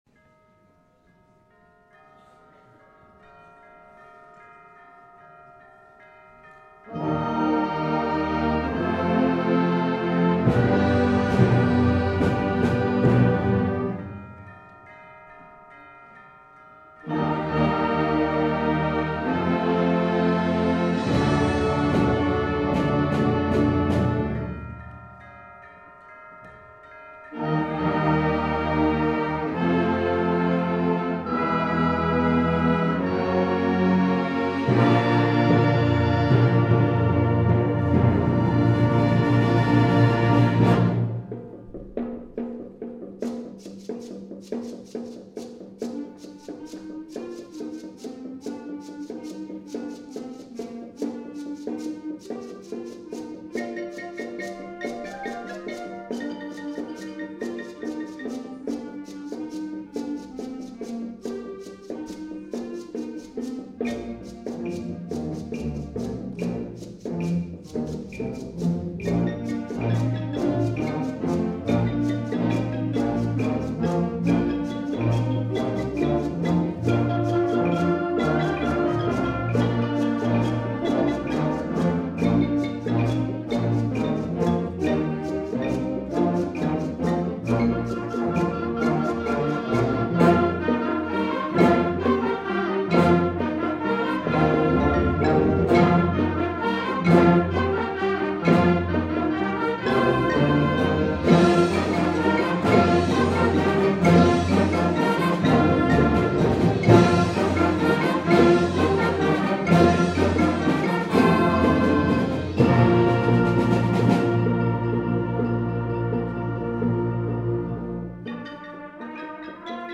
2013 Winter Concert